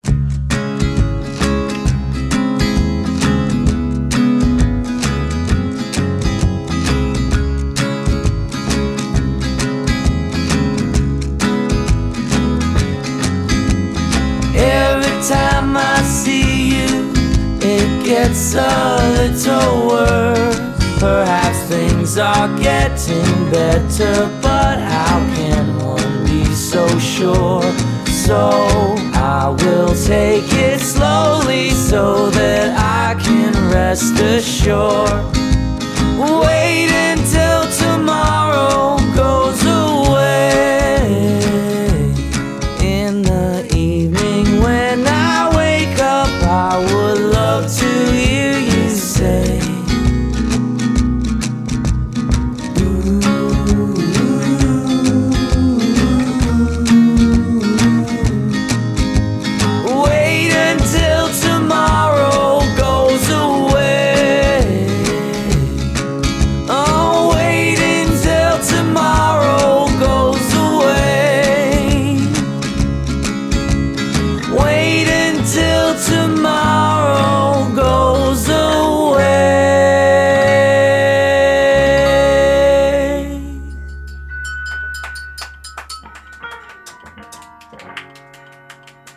Revolver flavored